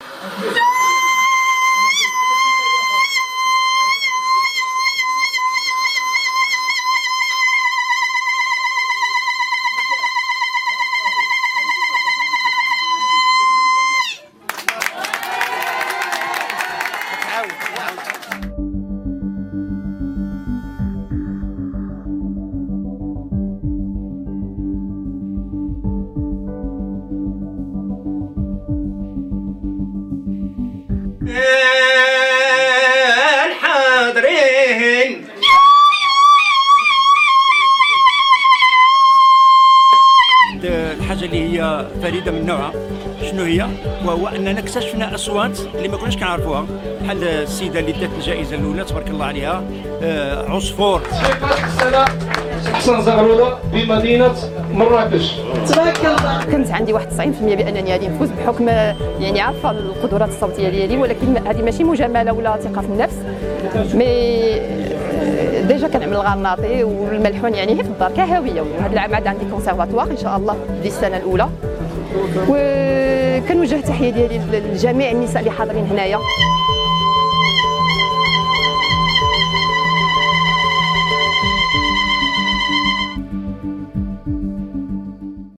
JDfvWMSNcsj_le-meilleur-youyou-au-maroc.mp3